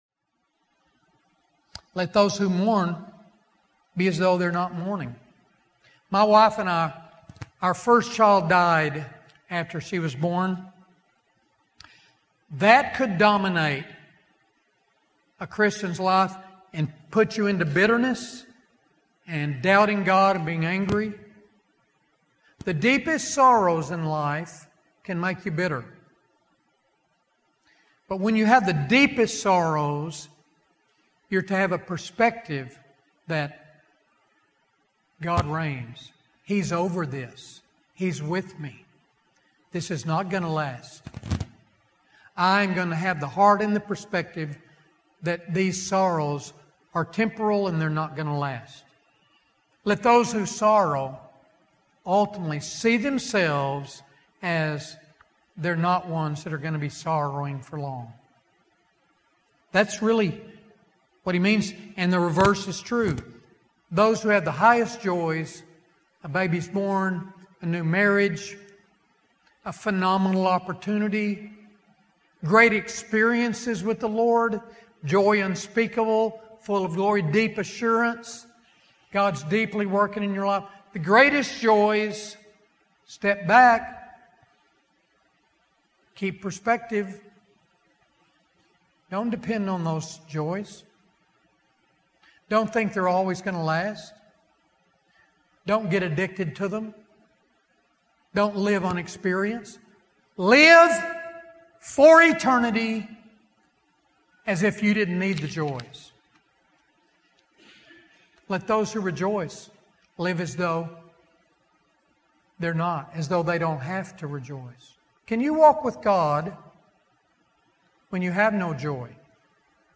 Excerpt taken from the full sermon, “ The Christian is Marked by Eternity” .